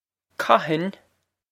Pronunciation for how to say
kah-hin?
This is an approximate phonetic pronunciation of the phrase.